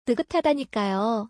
ヌタタダニカヨ